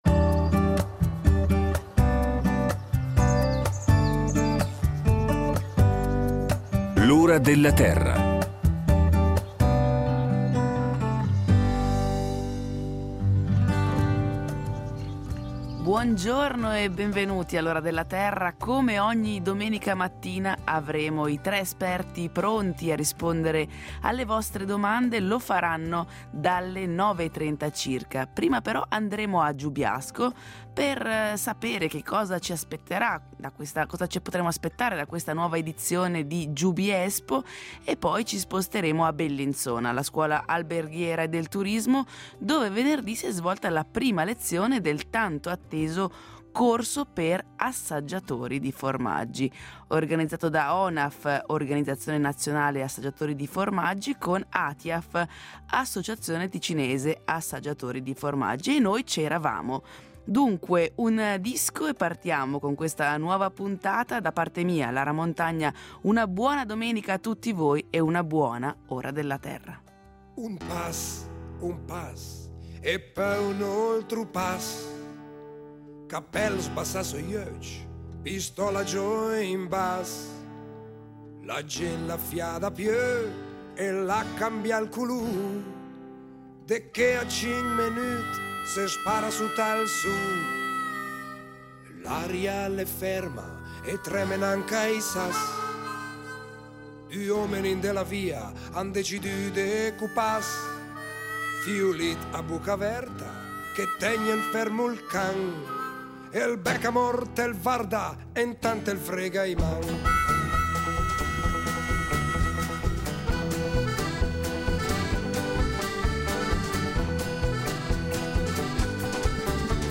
I tre esperti de L’Ora della Terra saranno presenti in studio per rispondere alle domande del pubblico da casa: